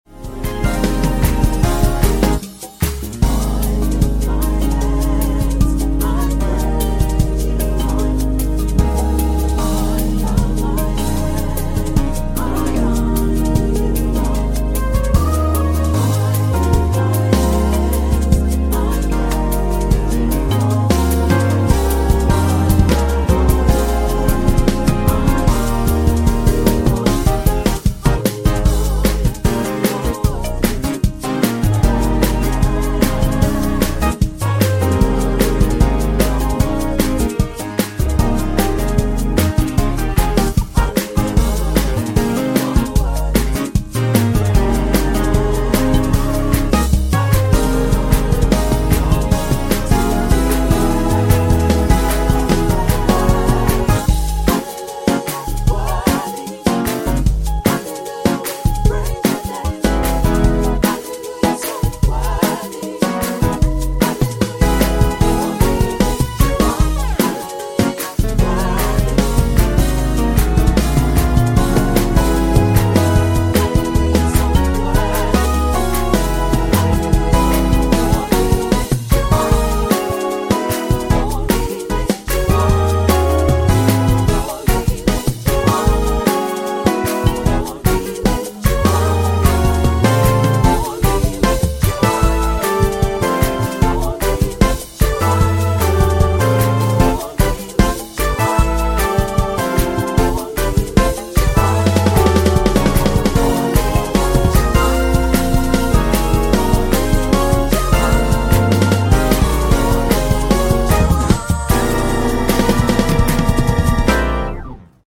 I Decided To Cover This Classic Song!